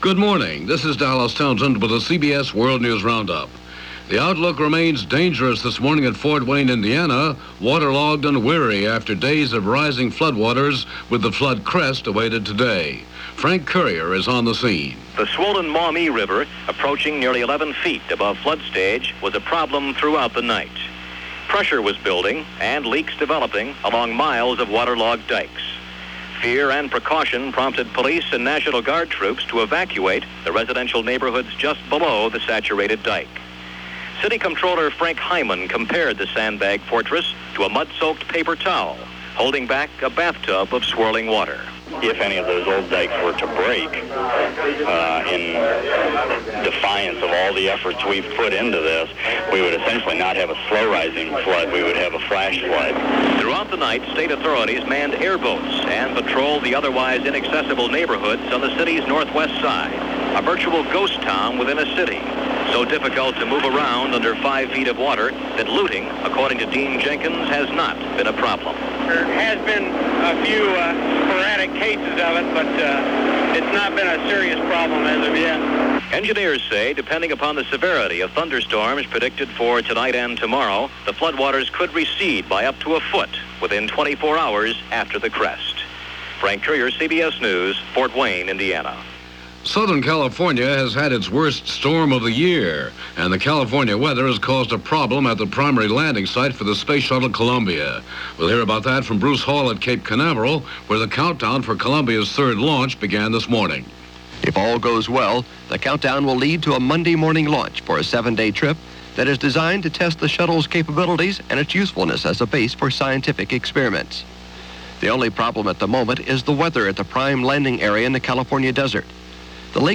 March 18, 1982 - Weather Report - Fort Wayne: Rain with Sandbags - Shuttle Columbia Heading To With Sands - News for this day in 1982.